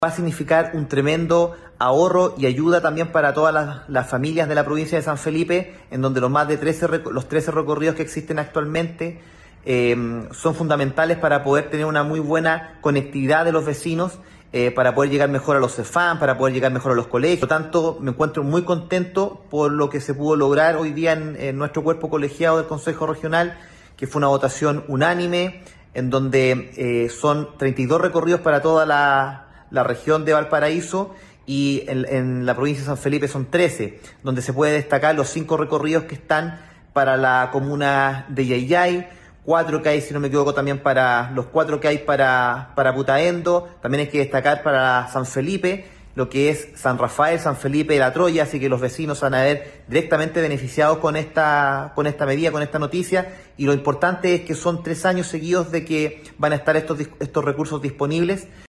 El Consejero Fernando Astorga valoró la cantidad de nuevos recorridos que beneficiarán a Llay Llay